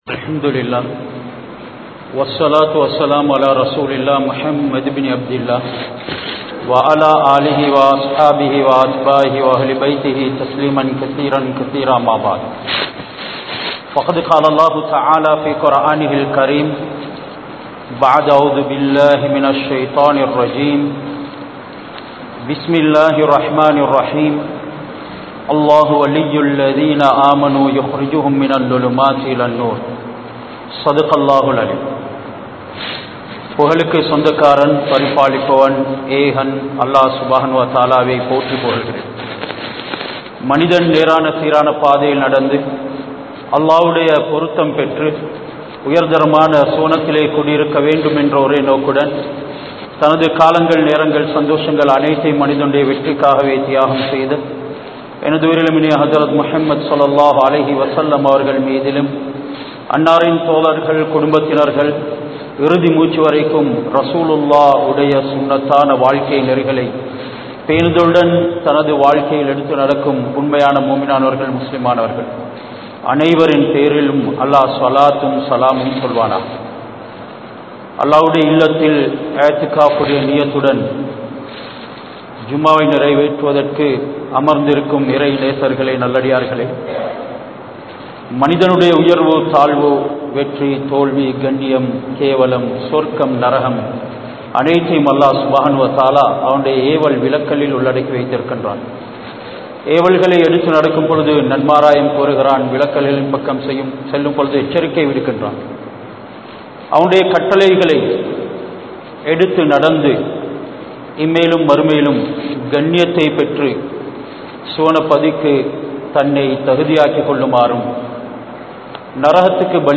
Islamiya Muraipadi Vaalungal (இஸ்லாமிய முறைப்படி வாழுங்கள்) | Audio Bayans | All Ceylon Muslim Youth Community | Addalaichenai